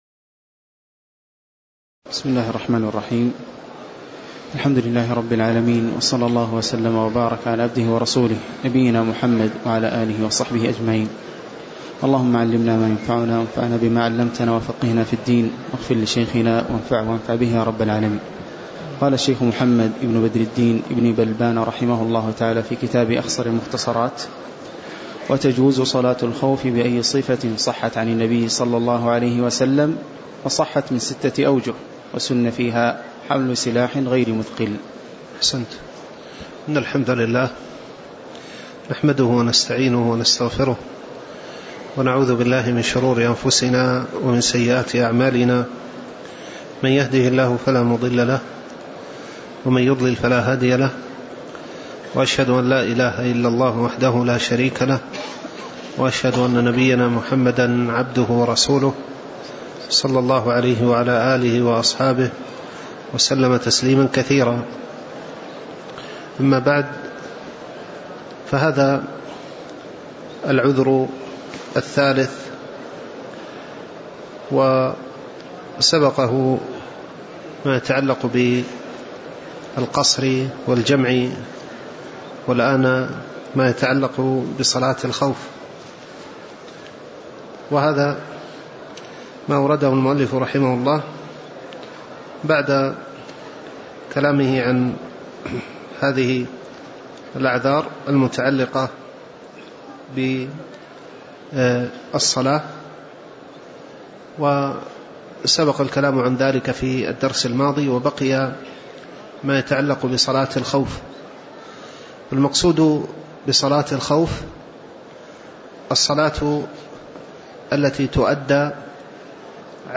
تاريخ النشر ١٧ رجب ١٤٣٩ هـ المكان: المسجد النبوي الشيخ